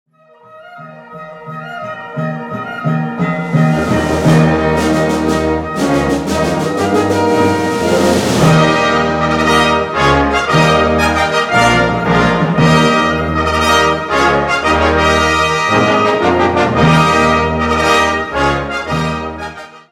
Catégorie Harmonie/Fanfare/Brass-band
en forme classique A-B-A.